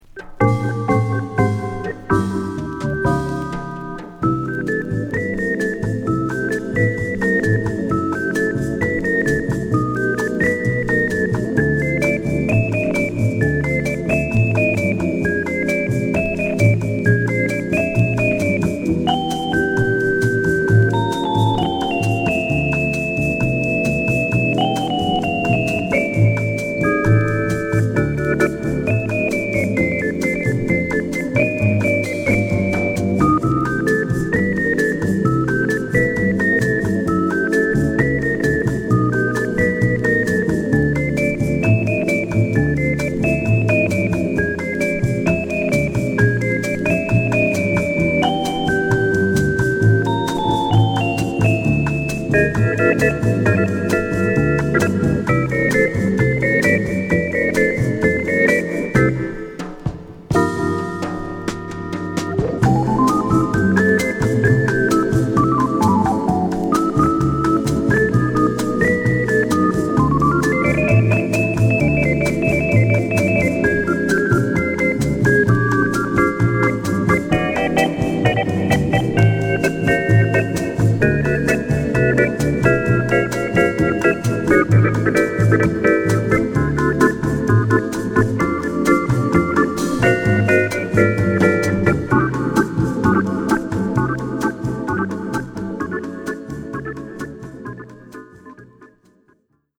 オルガンの響きが心地良すぎるメロウボッサ